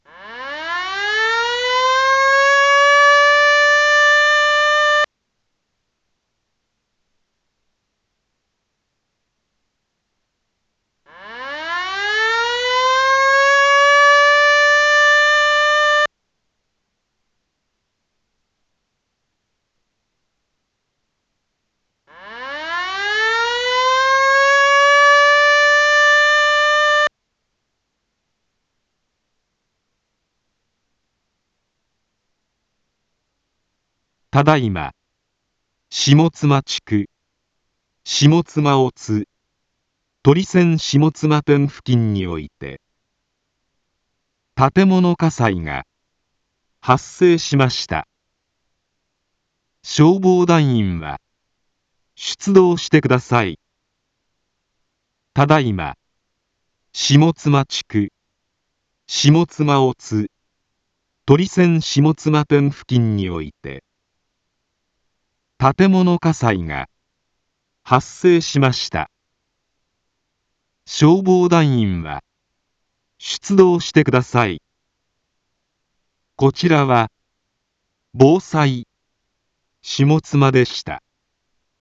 一般放送情報
Back Home 一般放送情報 音声放送 再生 一般放送情報 登録日時：2022-02-13 06:28:25 タイトル：火災報 インフォメーション：ただいま、下妻地区、下妻乙、とりせん下妻店付近において 建物火災が発生しました。